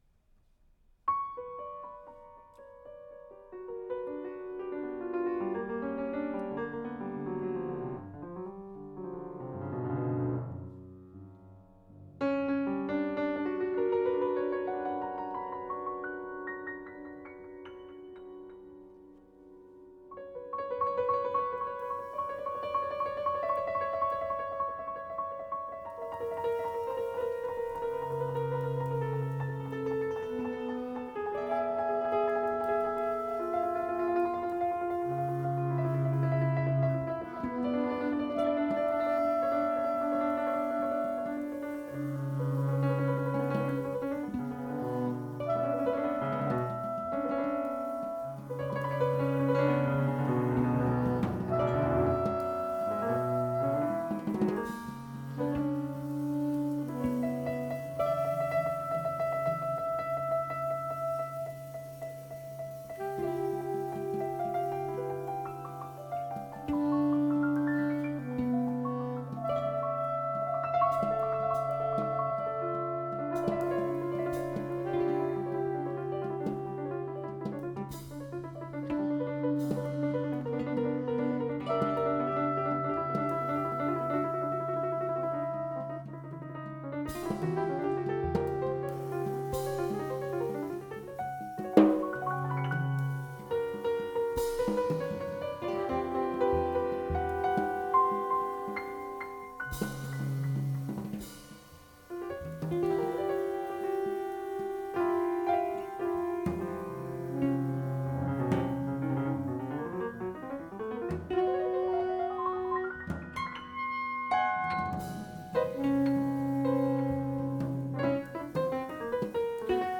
tenor and soprano saxophones
electric guitar
cello
drums